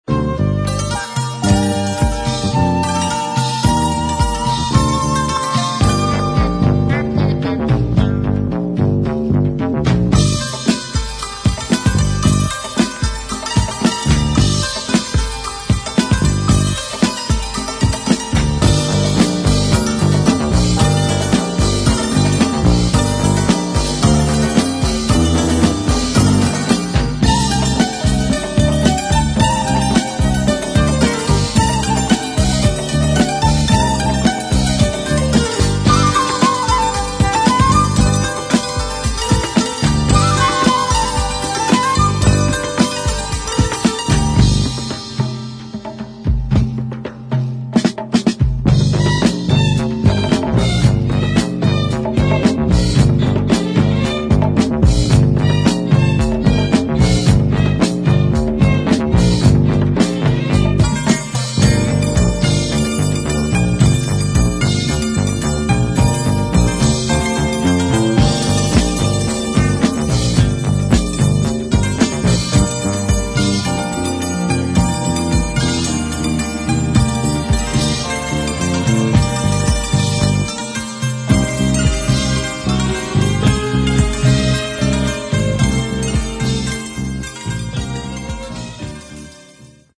DISCO / COSMIC PSYCH